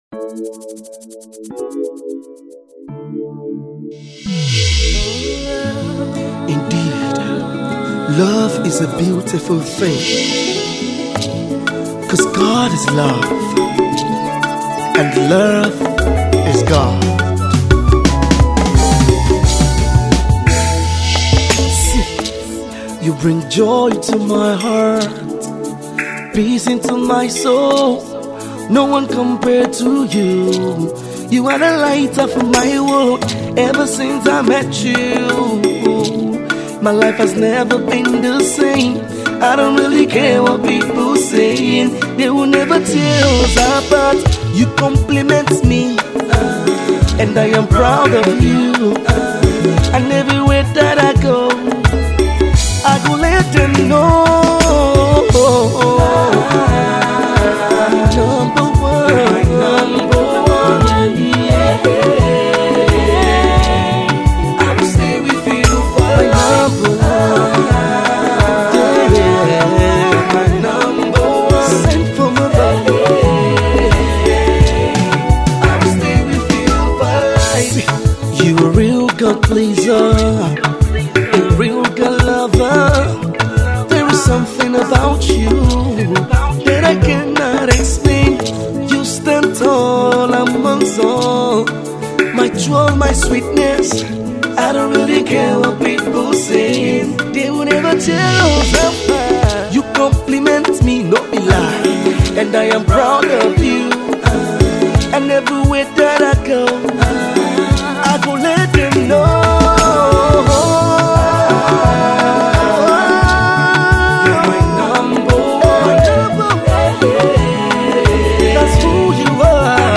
slow tempo love tune